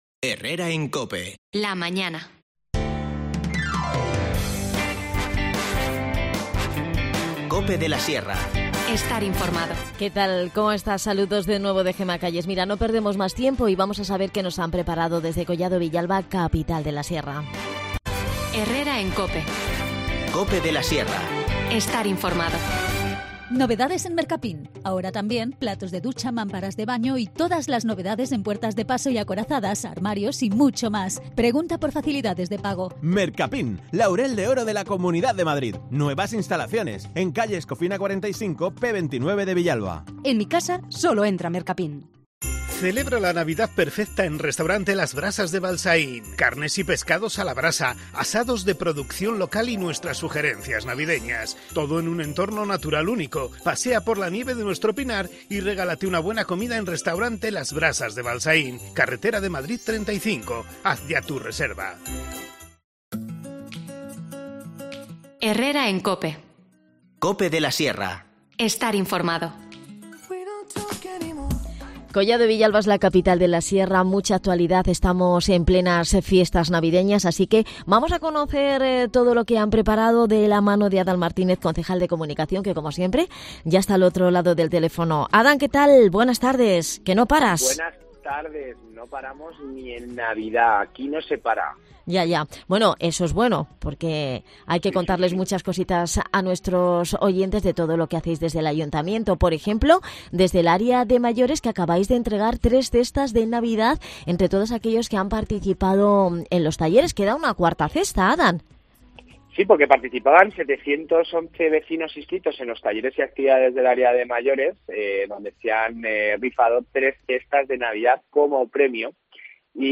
Hablamos con Adan Martínez, concejal de Comunicación, de éstas y otras cuestiones sobre la actualidad de Collado Villalba, Capital de la Sierra.